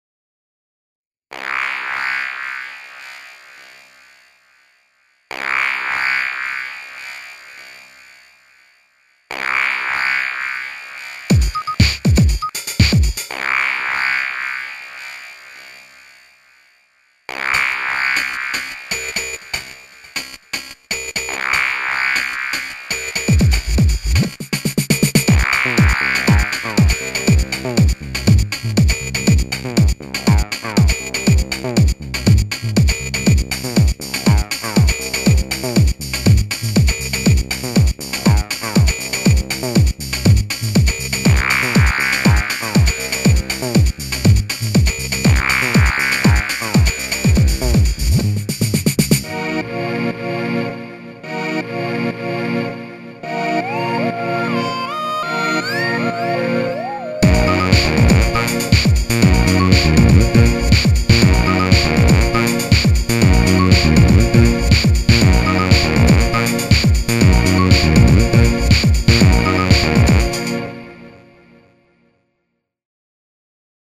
Syntrax也包含了合成器、采样器和音序器，拥有8通道立体声调节，双向循环的样本回放功能，带15种实时效果调节，如音频滤波器、变调、延迟等，内置音频样本编辑器，并拥有一个步进音序器。
它主要是使用Pattern来构成音乐，在界面里我们就可以看到它可以建立Pattern，并以此播放。
听听看Syntrax的声音是怎样的！